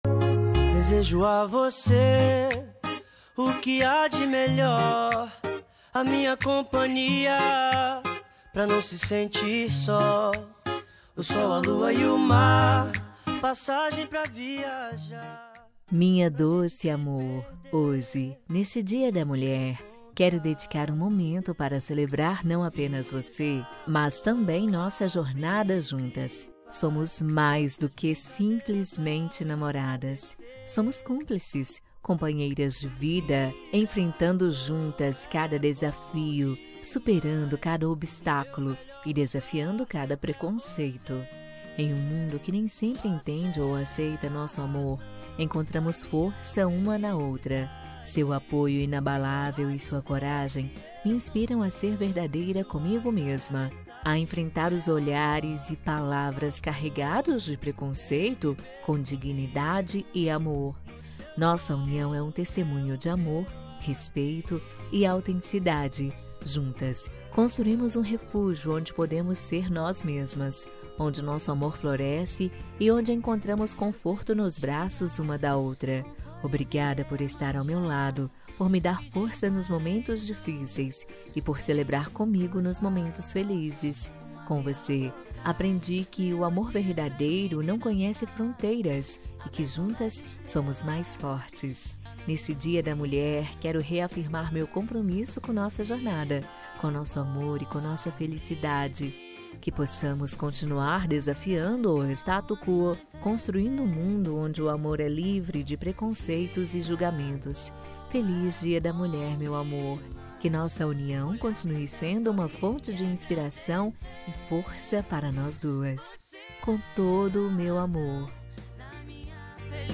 Dia da Mulher – Namorada – Feminina – Cód: 690605